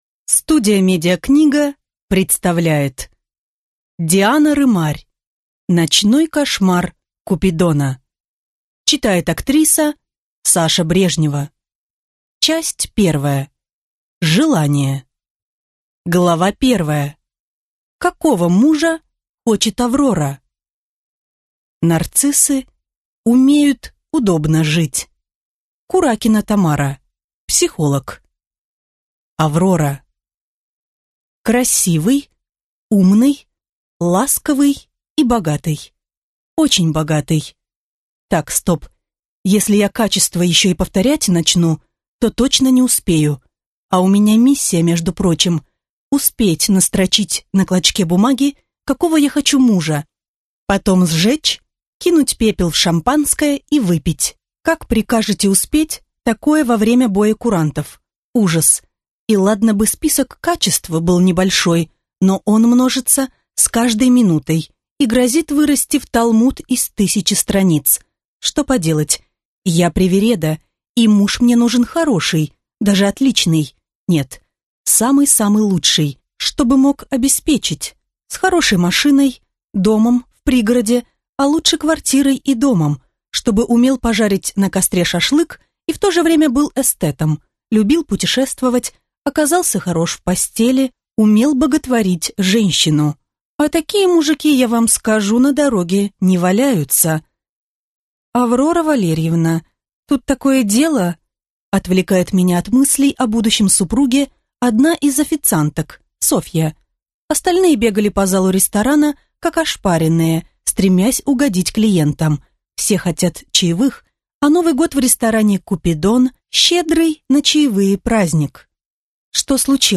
Аудиокнига Ночной кошмар Купидона | Библиотека аудиокниг